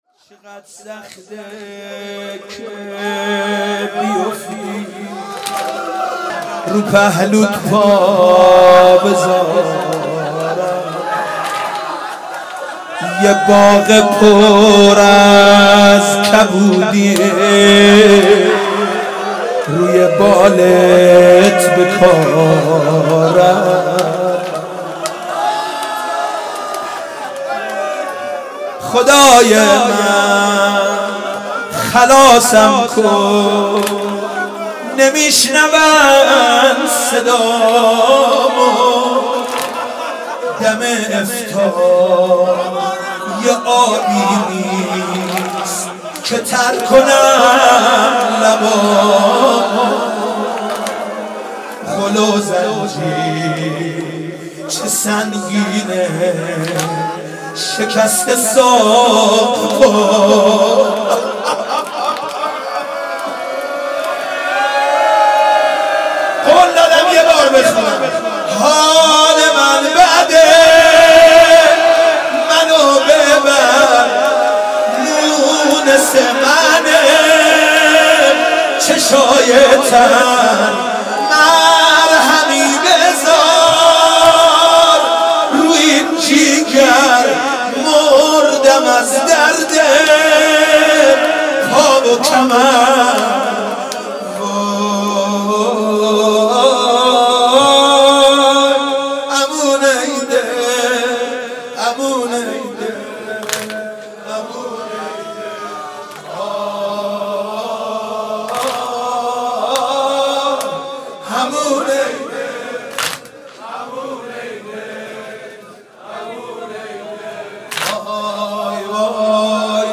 مدح امام کاظم علیه السلام